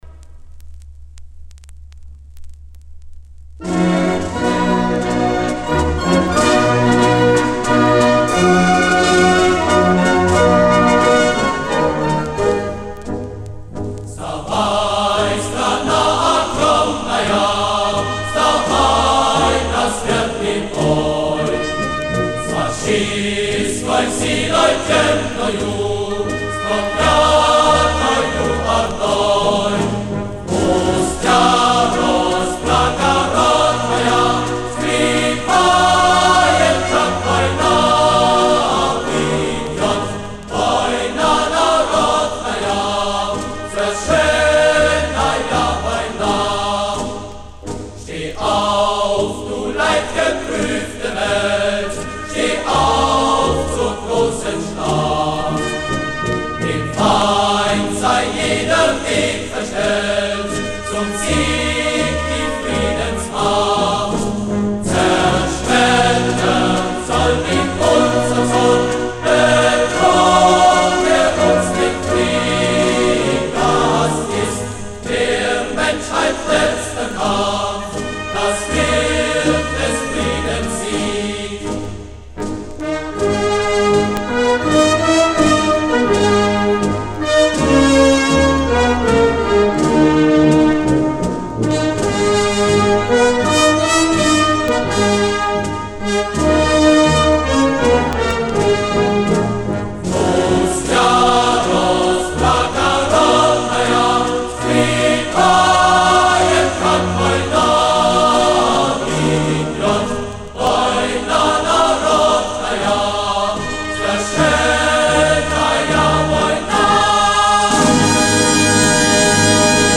Марши
Собственная оцифровка